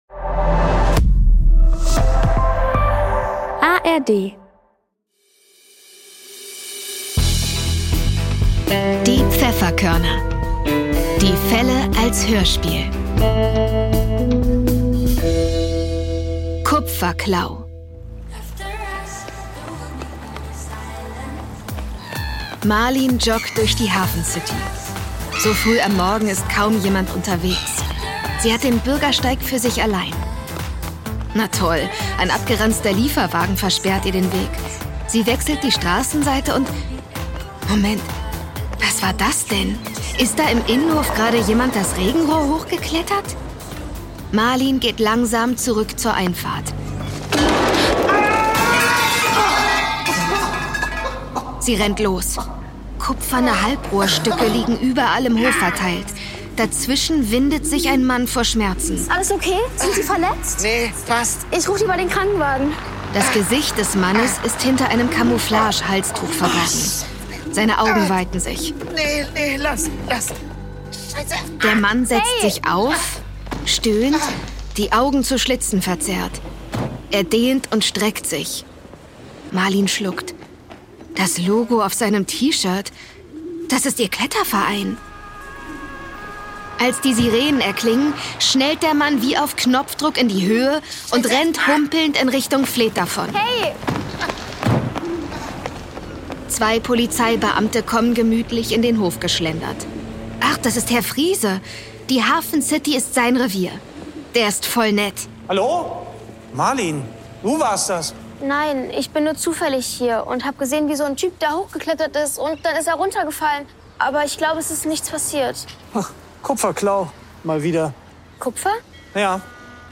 Kupferklau (9/21) ~ Die Pfefferkörner - Die Fälle als Hörspiel Podcast